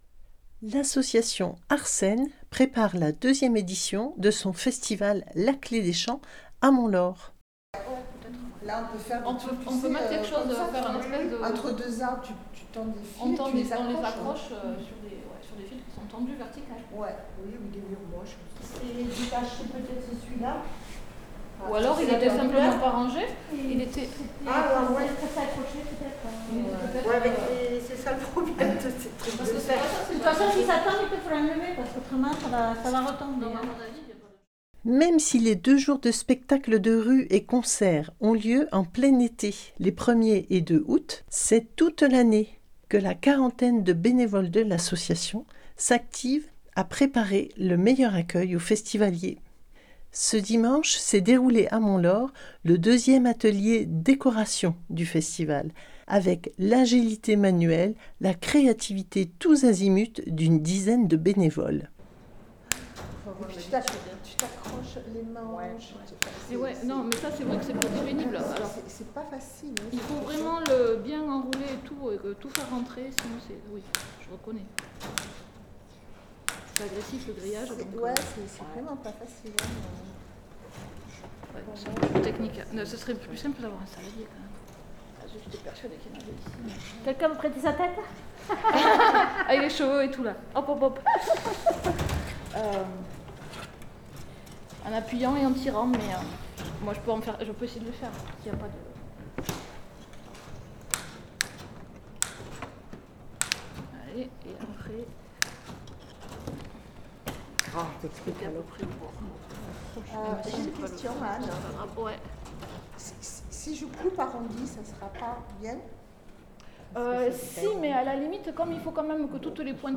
NEWS - Captation sonore lors de l'atelier décoration - Fabrication de nouveaux gradins pour le festival par la commission aménagement - Commission restauration dévoile les plats pour l'édition du festival 2025
atelier_deco.mp3